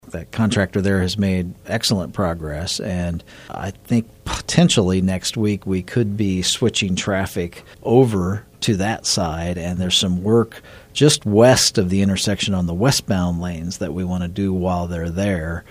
Manhattan City Manager Ron Fehr said on In Focus last week that construction is making progress in the three phases, especially on the eastbound lanes of Kimball between Manhattan Ave. and Tuttle Creek Blvd.